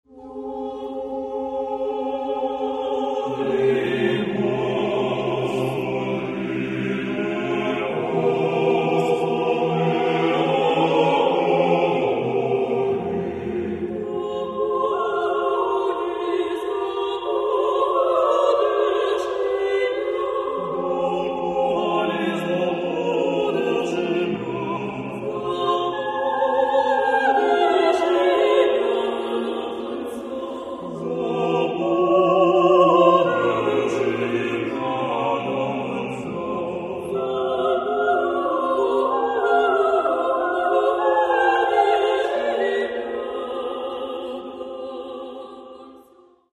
- Хорові концерти: